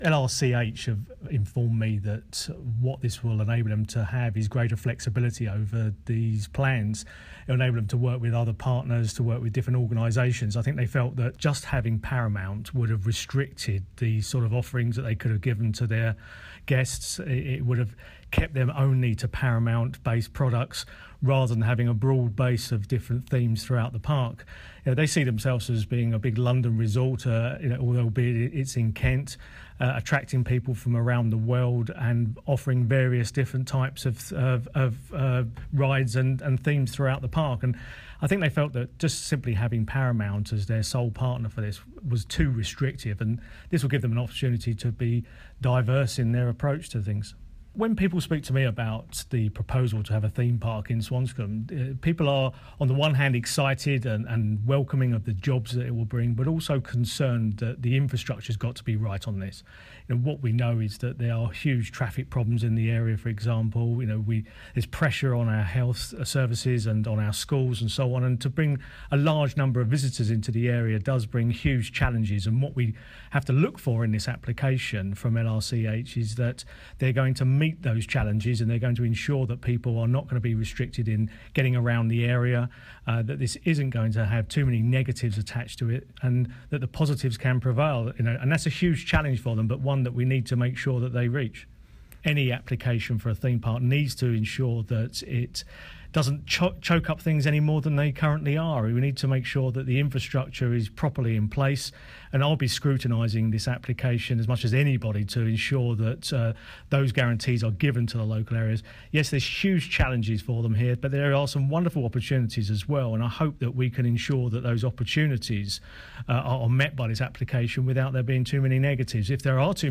Dartford MP Gareth Johnson gave us his reaction to the news that developers behind a massive theme park in north Kent have made a shock split with Paramount Pictures, the film studio which was to lend its name to the resort’s rides and attractions.